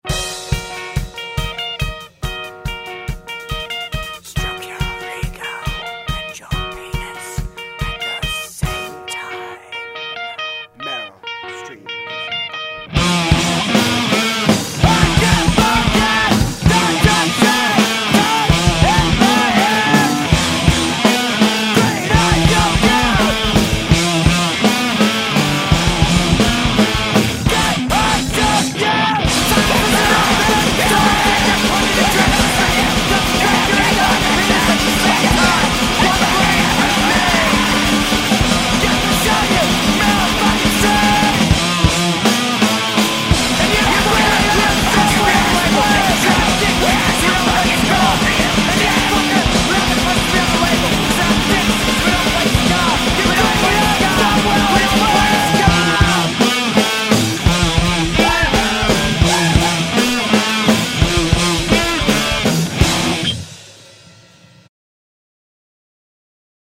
Left unmastered because we're beautiful